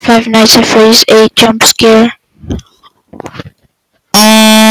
fnaf 8 jump fart
fnaf-8-jump-fart.mp3